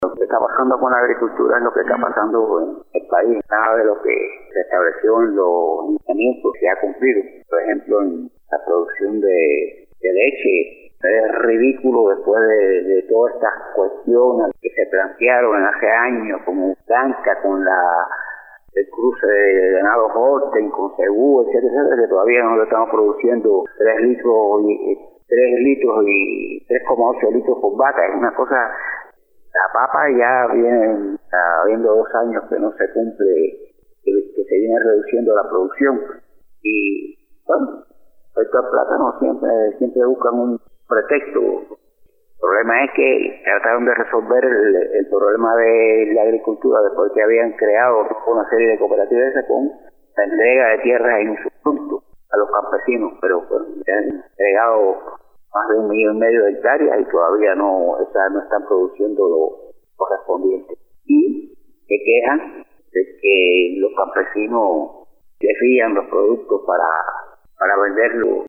economista
Entrevista